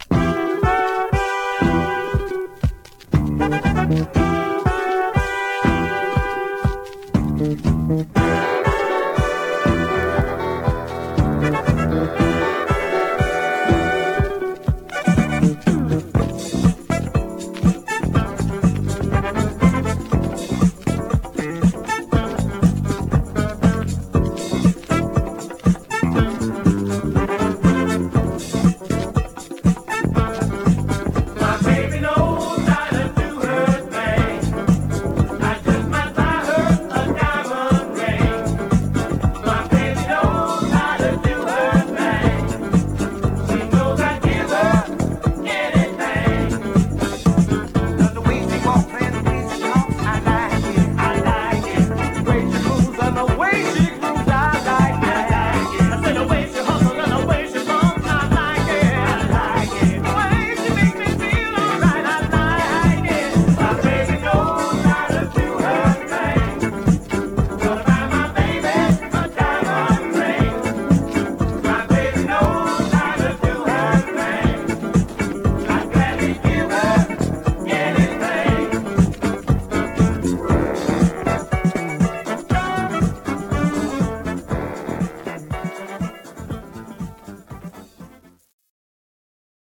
Mono
Funk